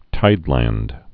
(tīdlănd)